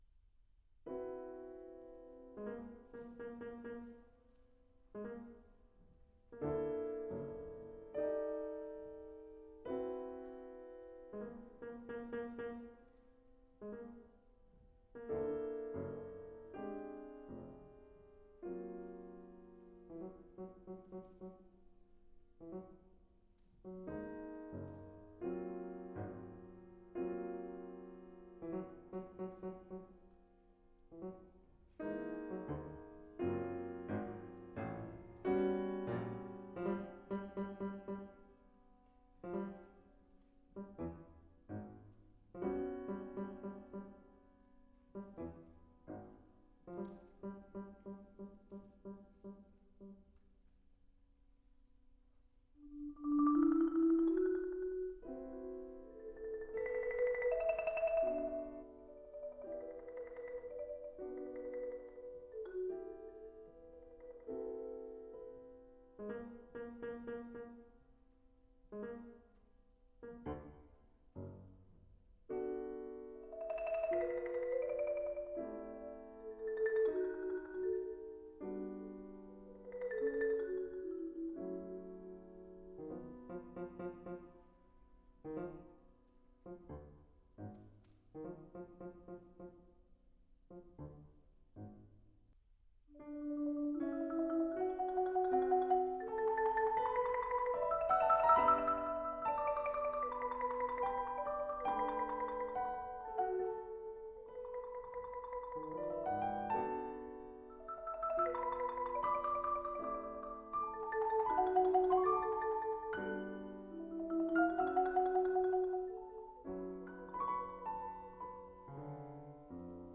マリンバ、打楽器奏者。
ピアノ伴奏